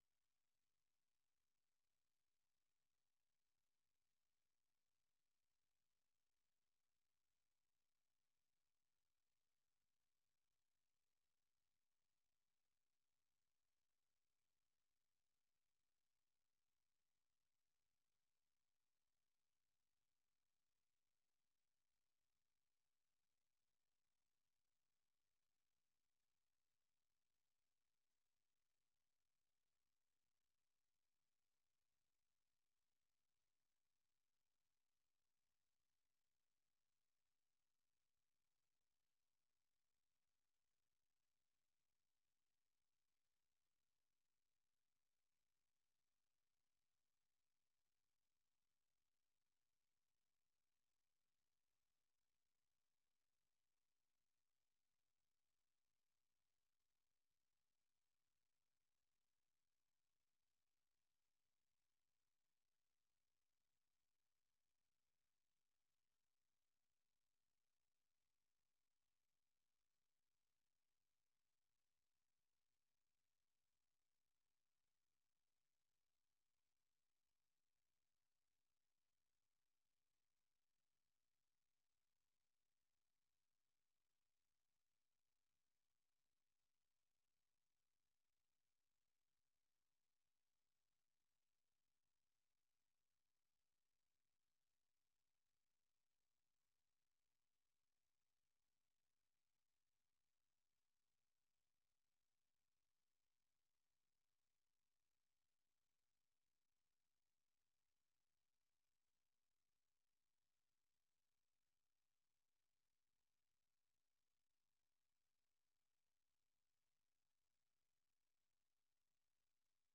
ཉིན་ལྟར་ཐོན་བཞིན་པའི་བོད་དང་ཨ་རིའི་གསར་འགྱུར་ཁག་དང་། འཛམ་གླིང་གསར་འགྱུར་ཁག་རྒྱང་སྲིང་ཞུས་པ་ཕུད། དེ་མིན་དམིགས་བསལ་ལེ་ཚན་ཁག་ཅིག་རྒྱང་སྲིང་ཞུ་བཞིན་ཡོད།